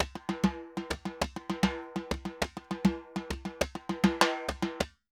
Timba_Candombe 100_3.wav